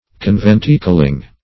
Search Result for " conventicling" : The Collaborative International Dictionary of English v.0.48: Conventicling \Con*ven"ti*cling\, a. Belonging or going to, or resembling, a conventicle.